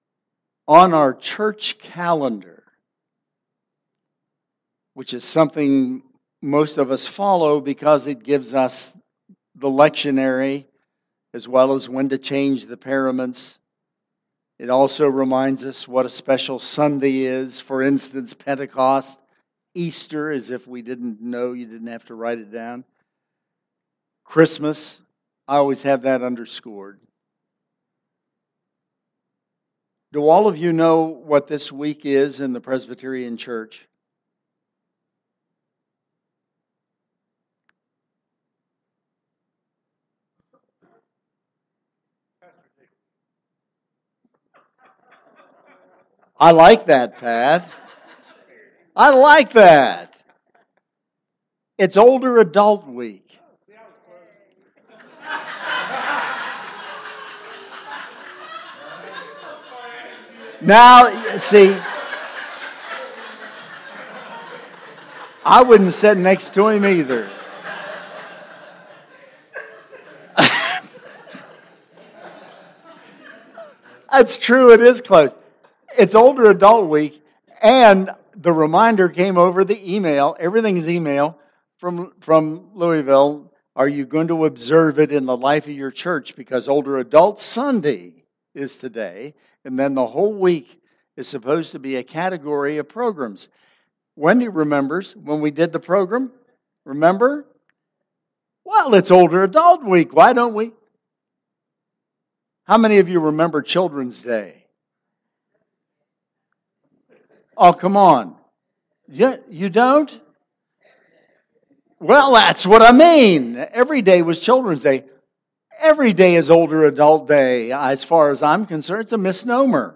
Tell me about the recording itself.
Sunday, May 3, 2015 – “God’s Love” :: McLeod Memorial Presbyterian Church